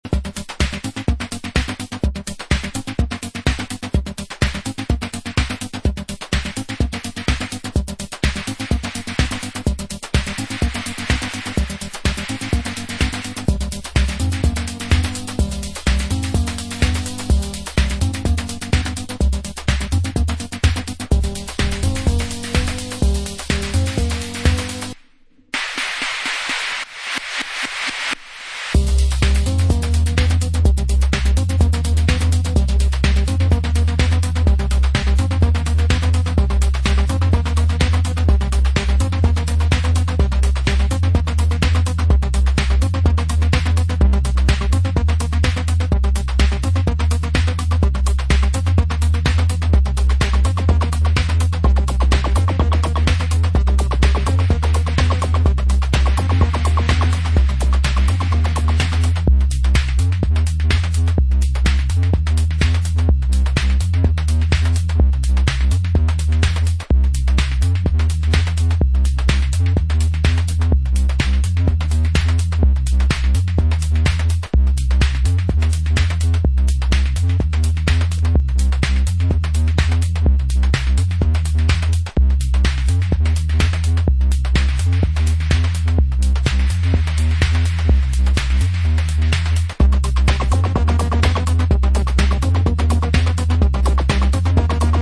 bass heavy single
bleep infested remix